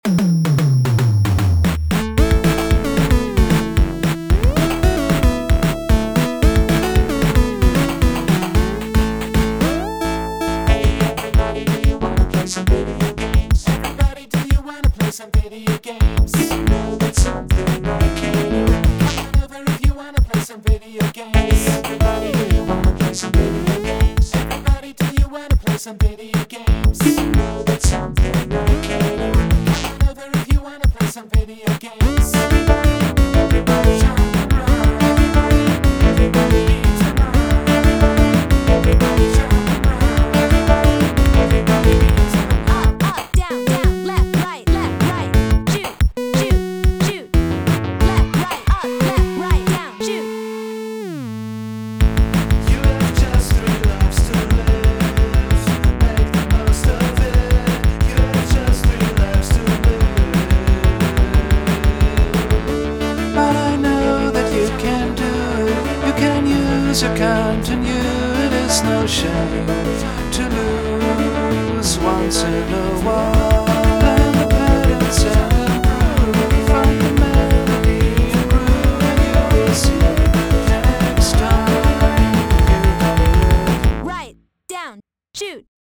Kurzer 8-Bit Popsong
Ich hab soeben einen Rough-Mix eines kleinen Lo-Fi-Popsongs über Videospiele fertiggestellt.